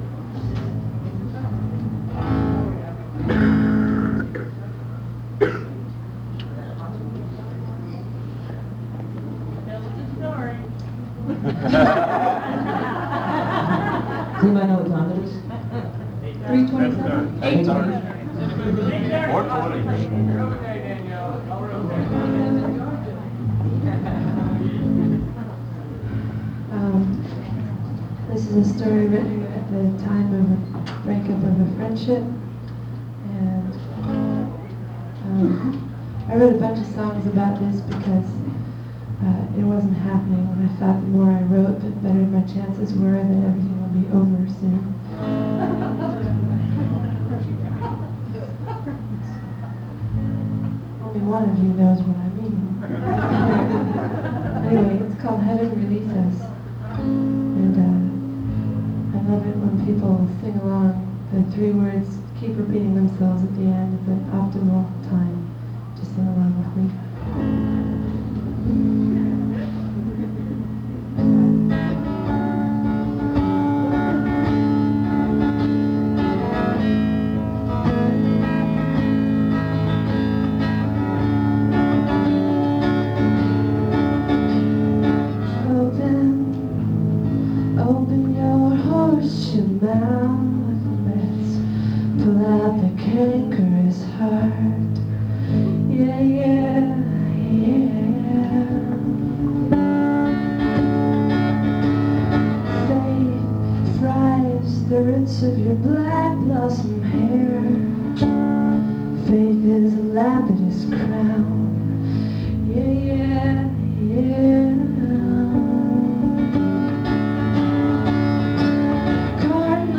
(songwriters in the round)
(first set)